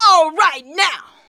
ALRIGHT.wav